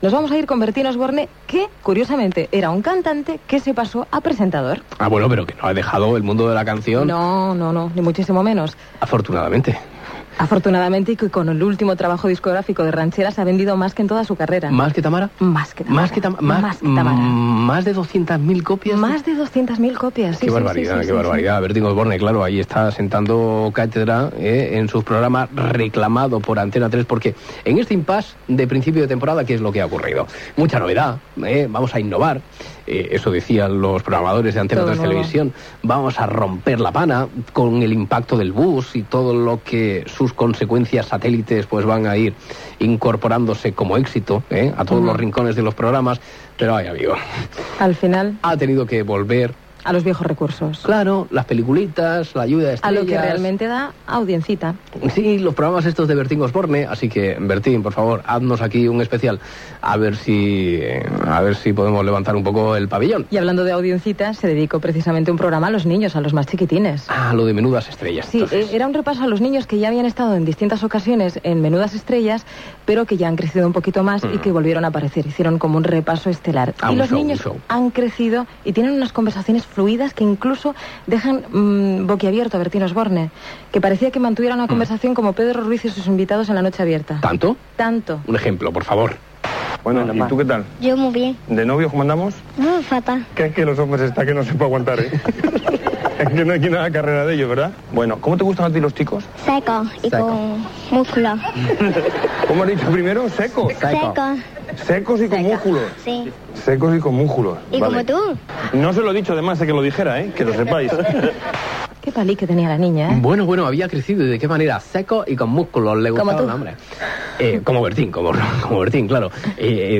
Secció de crònica televisiva. Parlen de Bertín Osborne a la televisió, dels programes "Menudas estrellas", "El bus"
Entreteniment